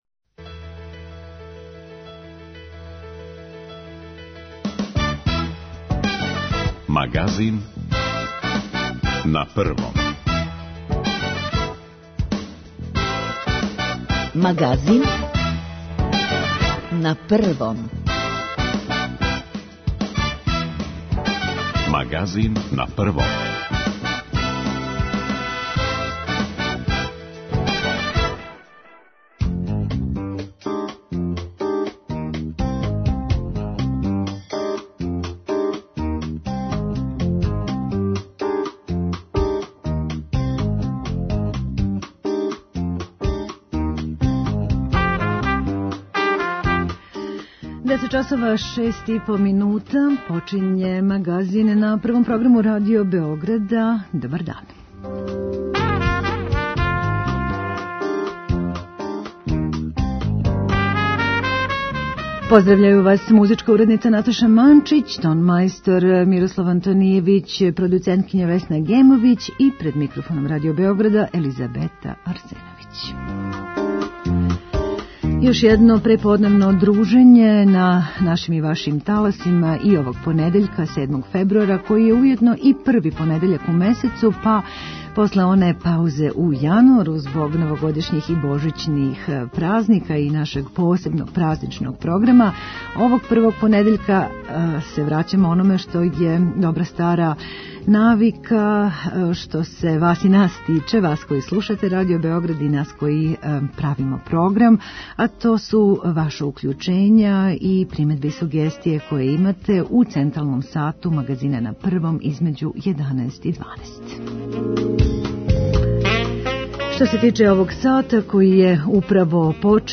Ви говорите, ми слушамо - контакт-програм Радио Београда 1!